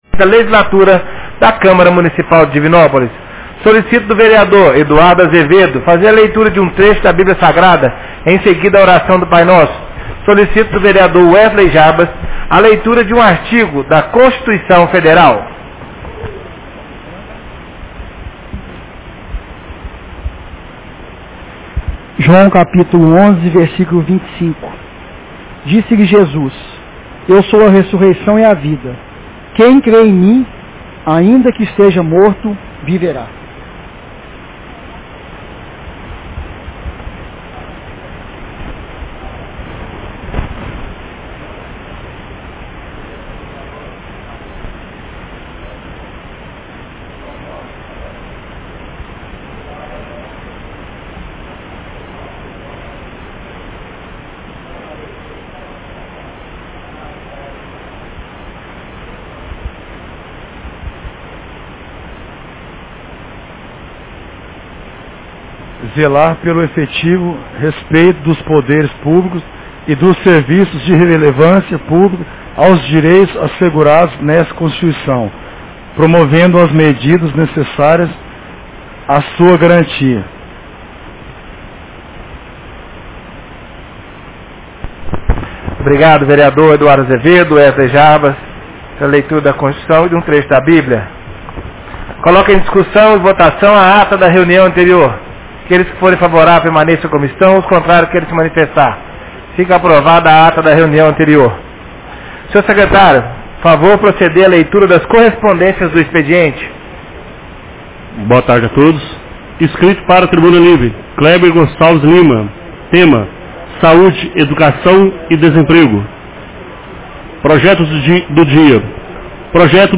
Reunião Ordinária 16 de 25 de março 2021